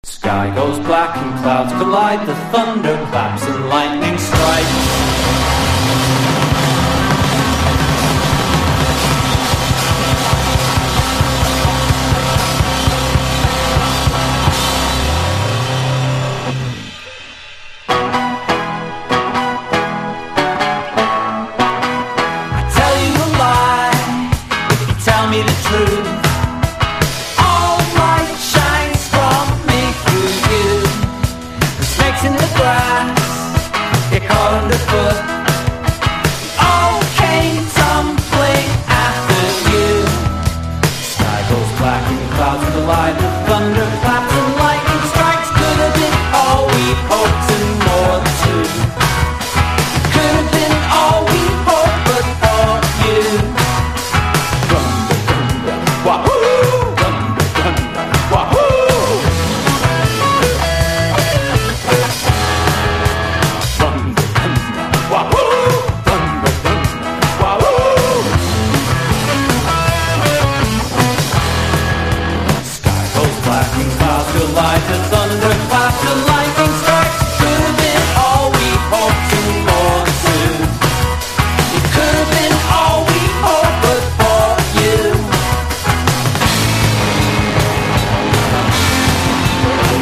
1. 00S ROCK >
NEO ACOUSTIC / GUITAR POP
ひねくれカラフル・ポップチューン！！